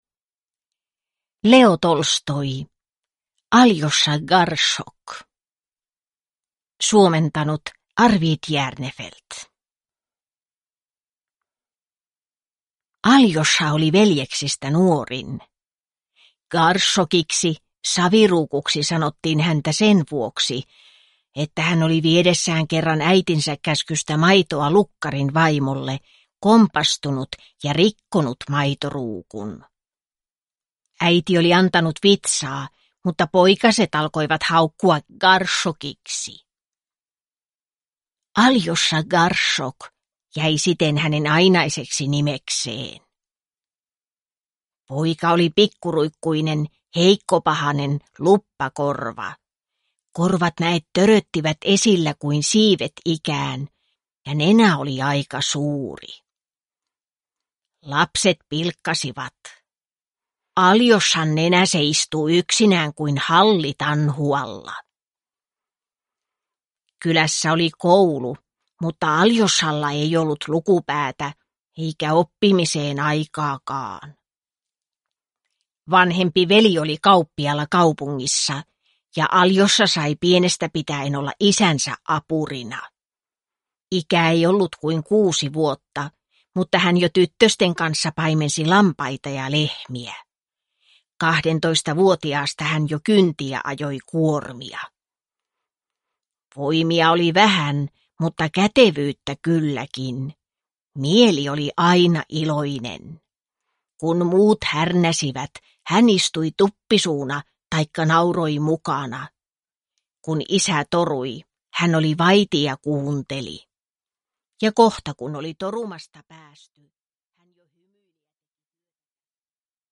Aljosha Garshok (ljudbok) av Leo Tolstoi